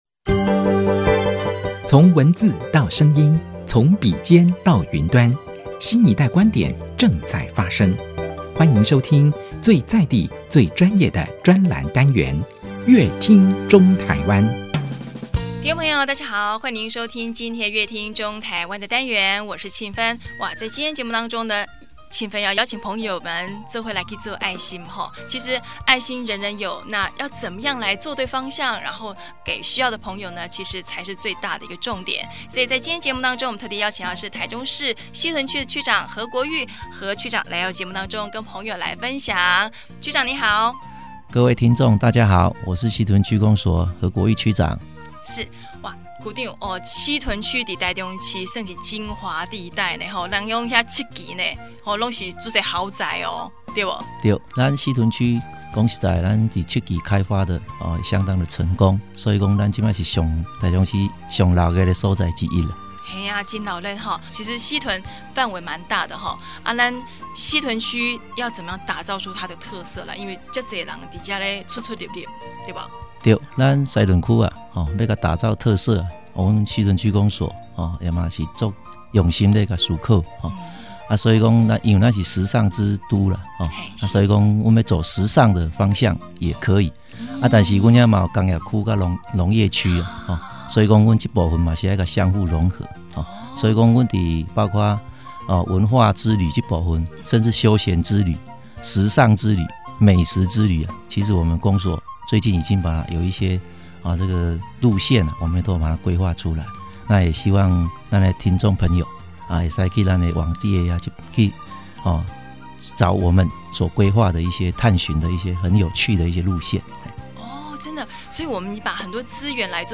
本集來賓：臺中市西屯區何國裕區長 本集主題：愛在西屯 點亮幸福 本集內容： 位於台中市精華地帶的西屯區，有時尚、有工業，也有農業，更是臺中市政府的所在地，但要如何打造西屯區的特色呢?西屯區公所何國裕區長，非常用心打造美食、時尚、文化之都，在西屯區公所網站羅列各類型遊程，引領大家從各面向了解西屯之美，打造幸福之都。